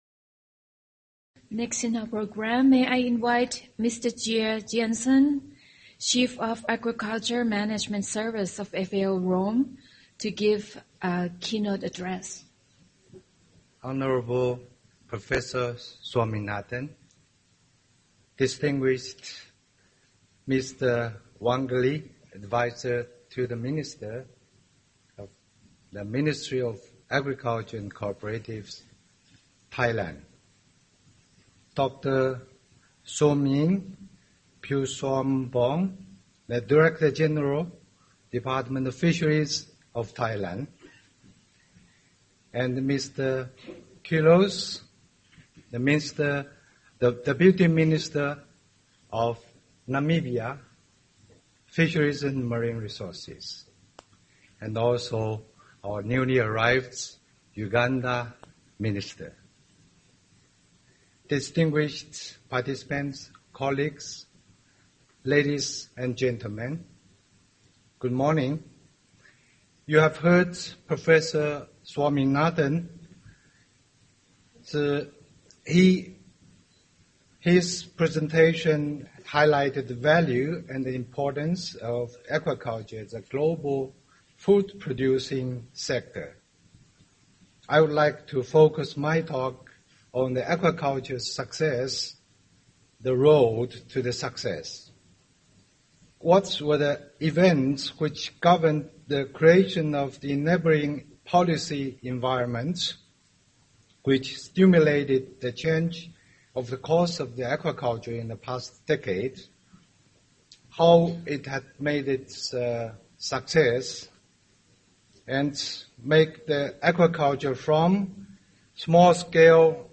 Keynote presentation on global aquaculture development since 2000